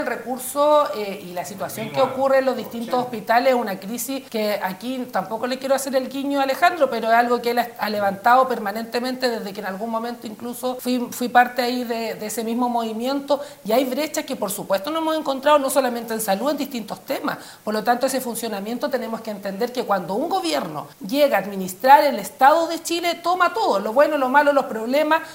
En el último programa de El Tablón de Canal 9, los parlamentarios de derecha Flor Weisse y Leónidas Romero, en conjunto con el pre candidato a diputado Alejandro Navarro y la seremi de Gobierno Jacqueline Cárdenas, abordaron la solicitud de dineros de la Subsecretaría de Redes Asistenciales a los GORE.